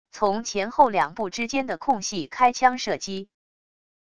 从前后两部之间的空隙开枪射击wav音频